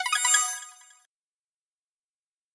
Звуки включения устройств
Электронный звуковой сигнал включения объекта